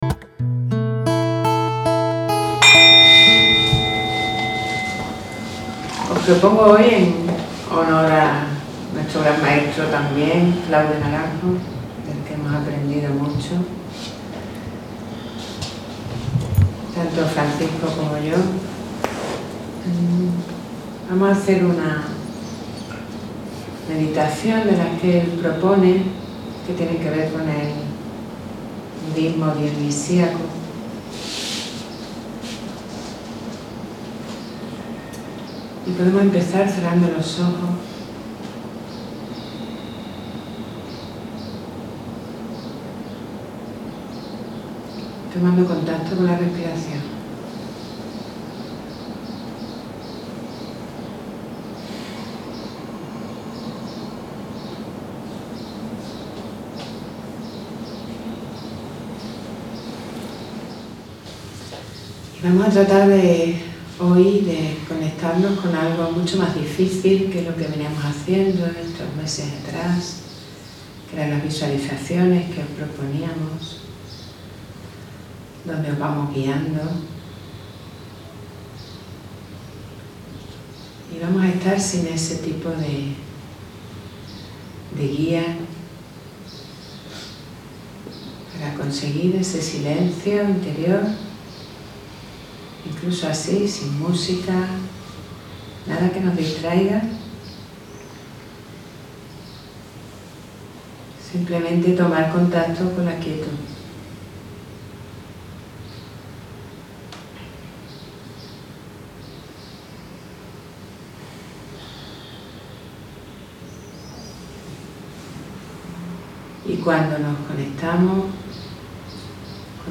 1-Meditación-Silencio-Interior-M10.mp3